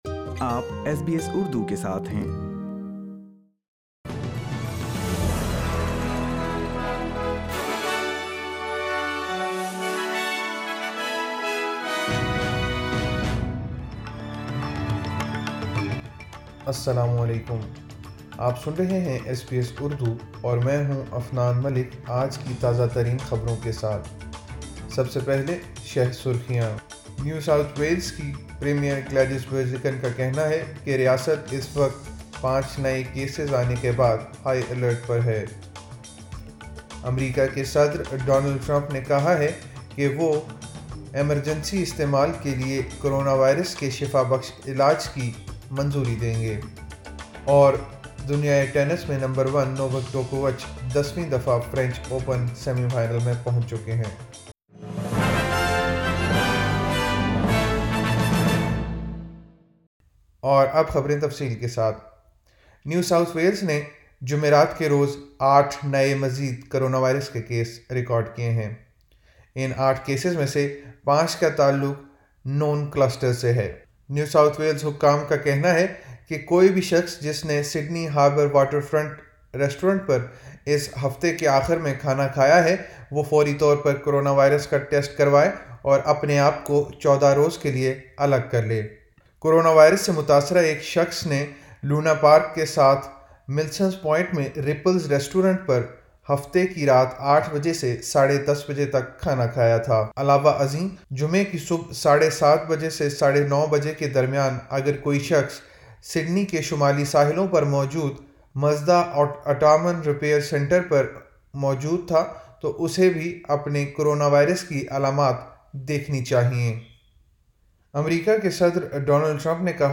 ایس بی ایس اردو خبریں 08 اکتوبر 2020